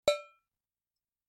دانلود آهنگ مزرعه 6 از افکت صوتی طبیعت و محیط
دانلود صدای مزرعه 6 از ساعد نیوز با لینک مستقیم و کیفیت بالا
جلوه های صوتی